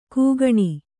♪ kūgaṇi